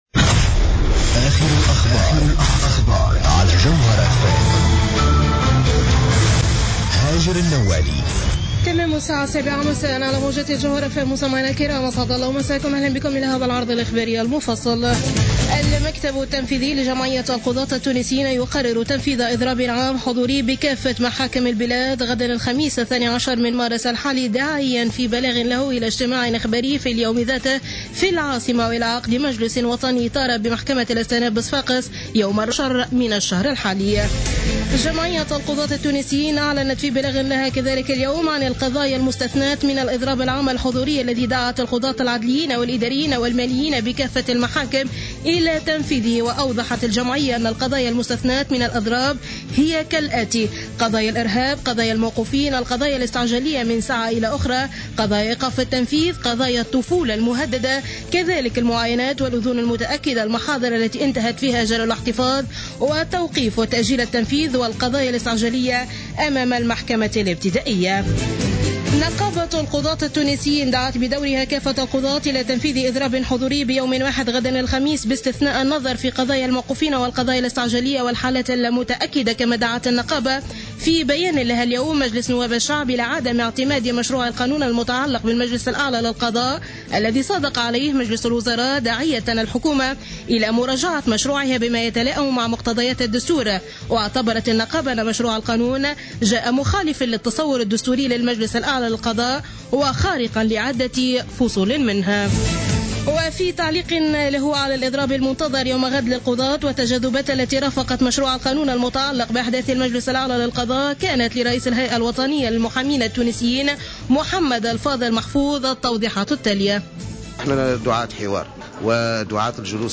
نشرة أخبار السابعة مساء ليوم الاربعاء 11 مارس 2015